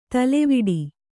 ♪ taleviḍi